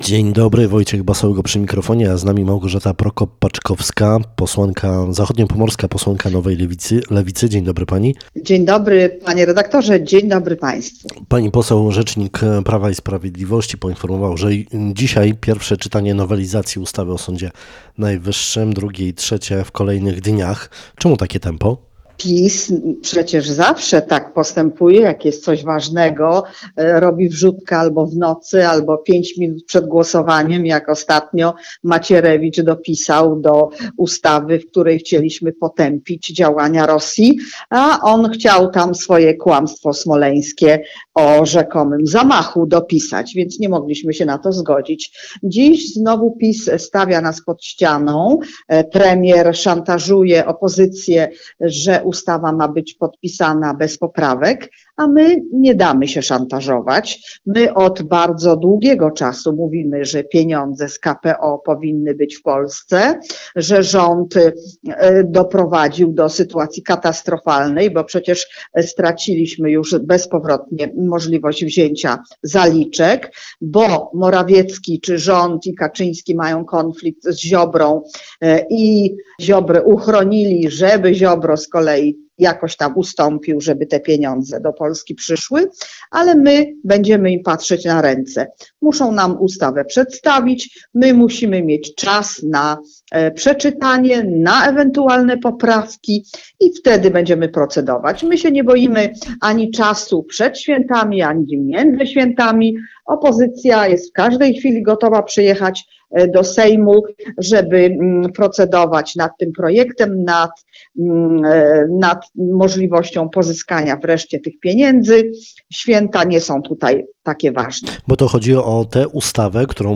– Nie pozwolimy na demontaż systemu ochrony zwierząt w Polsce – oświadczyły na wspólnej konferencji prasowej posłanki Lewicy, Inicjatywy Polskiej oraz Partii Zieloni.